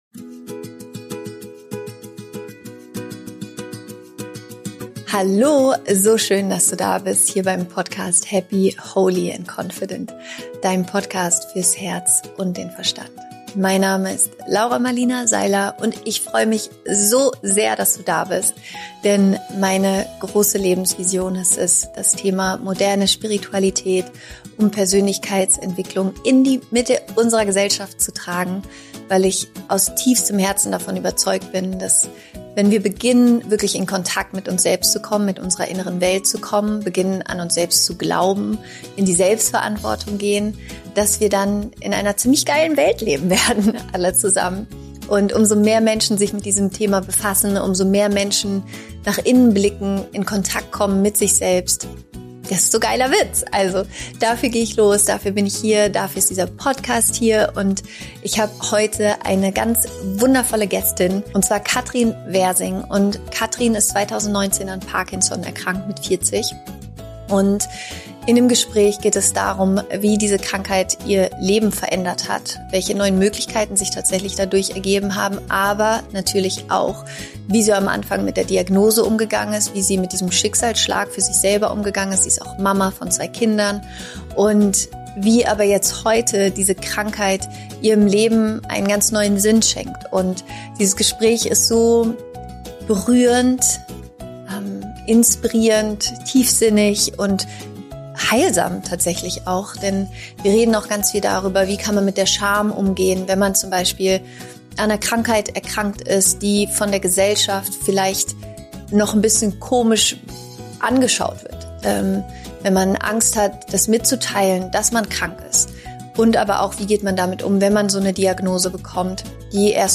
Kraft schöpfen aus Lebenskrisen – Interview